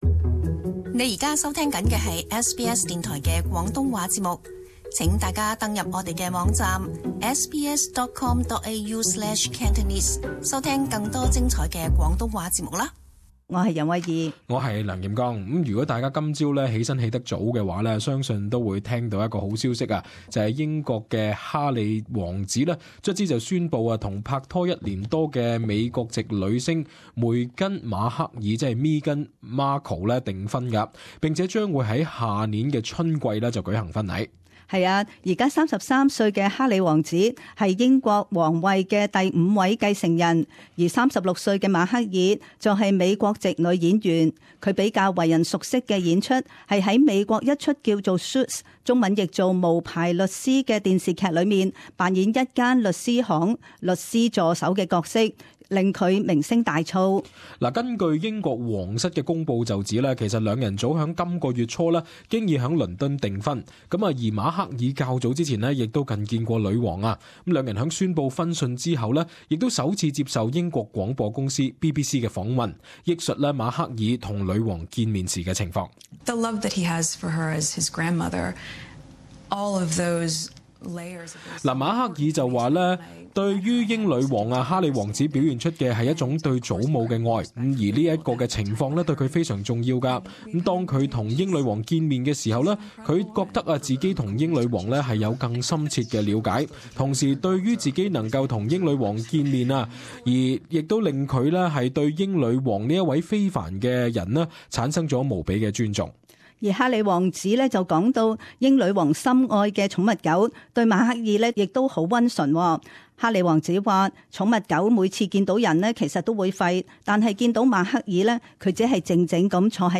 【時事報導】英國哈里王子與美國女星宣布婚訊